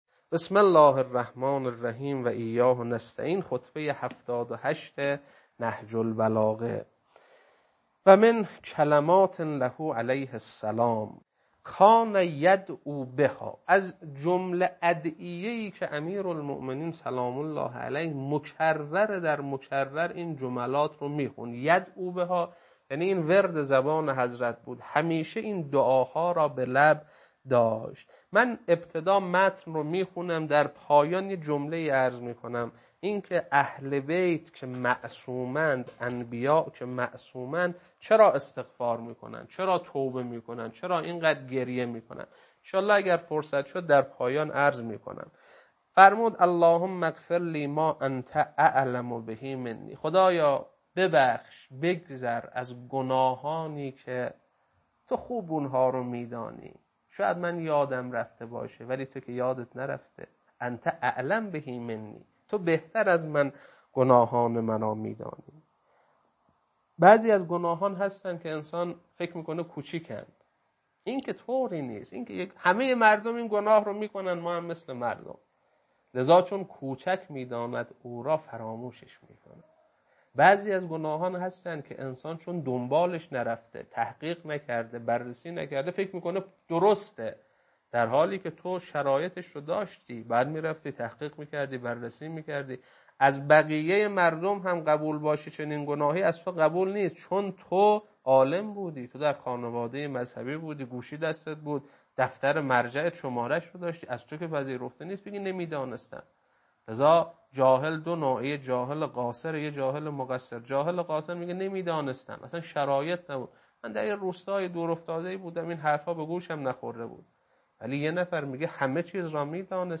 خطبه 78.mp3